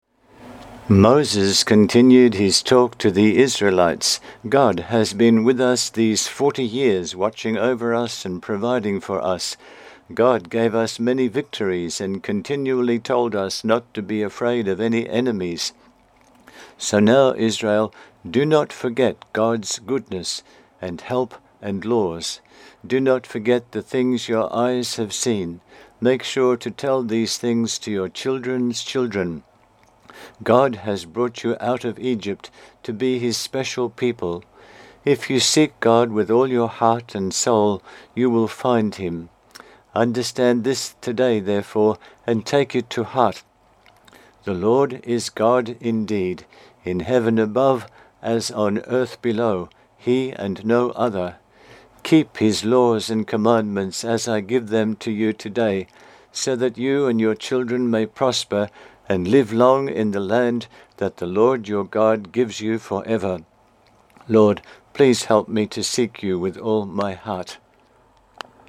My recording of this reading